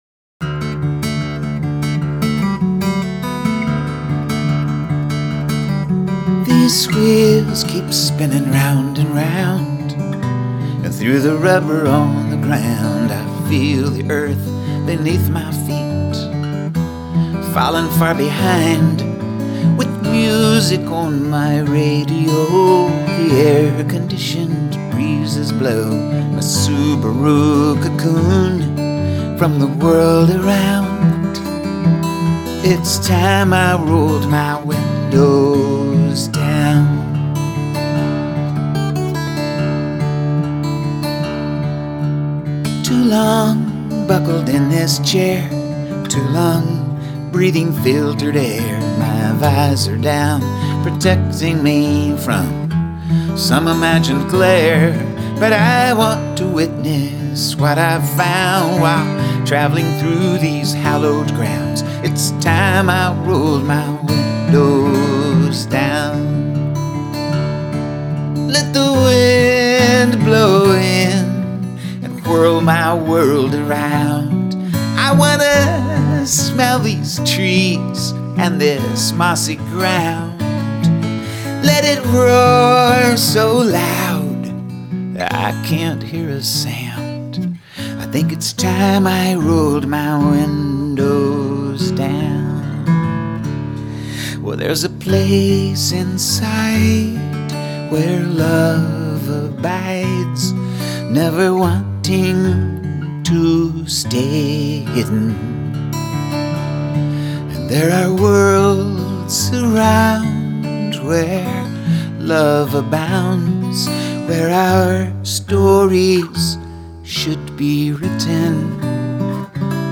with a single acoustic guitar